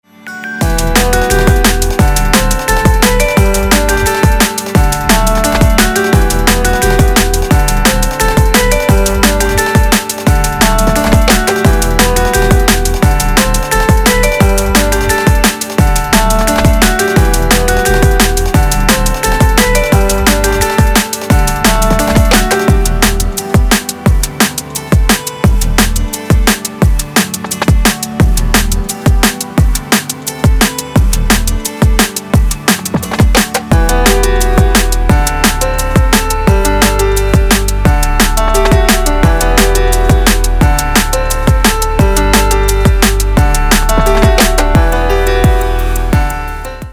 DubStep / DnB рингтоны